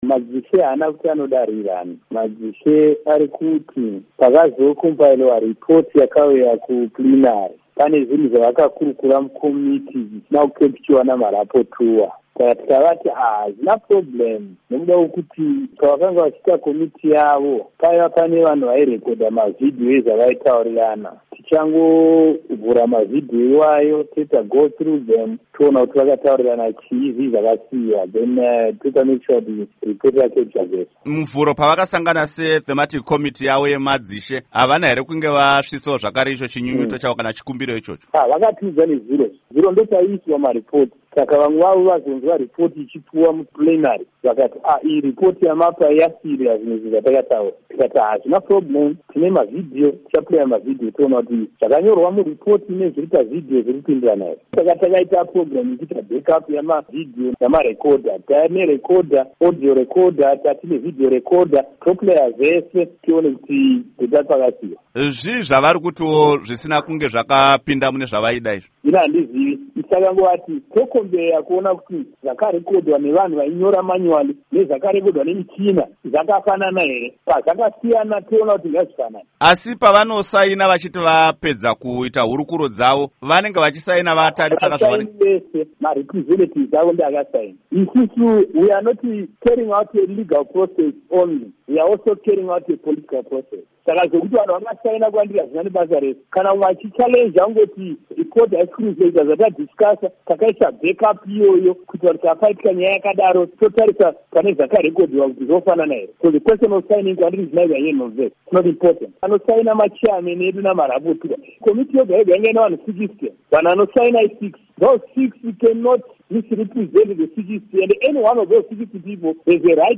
Hurukuro naVaMunyaradzi Paul Mangwana